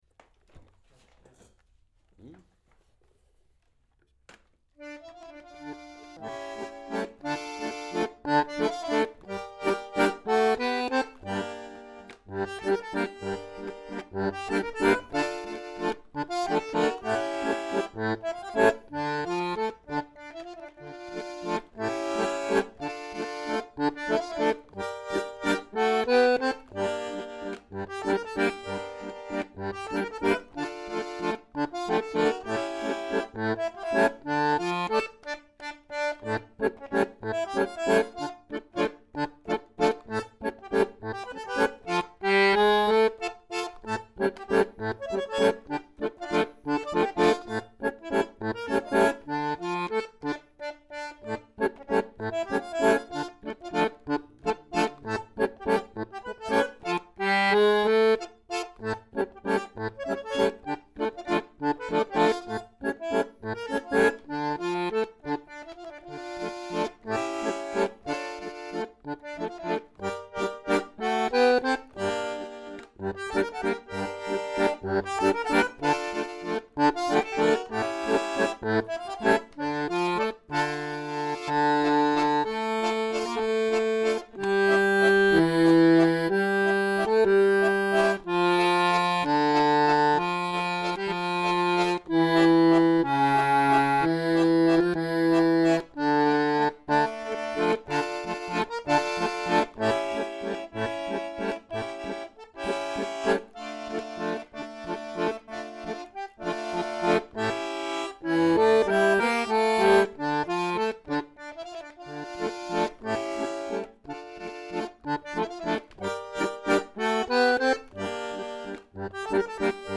Es, sin embargo, un bandoneonista muy bueno con una forma de tocar muy expresiva.